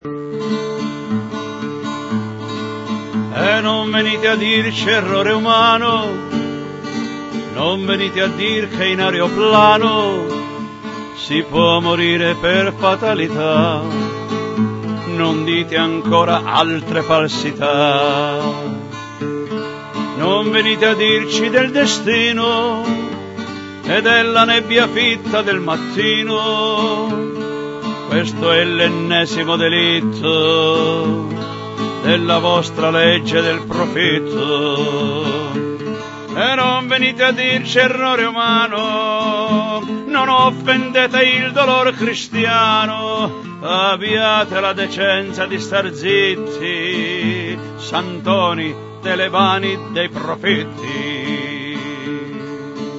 cantastorie
una ballata